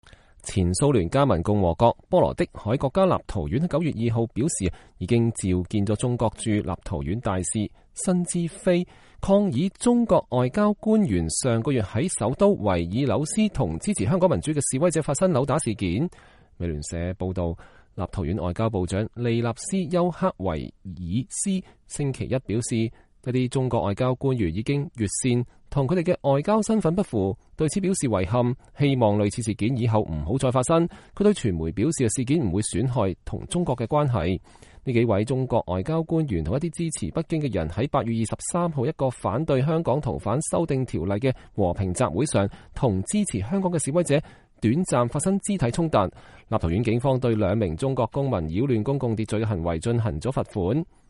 在波羅的海國家立陶宛首都維爾紐斯，支持與反對香港民主示威的兩派人展開辯論。（2019年8月23日）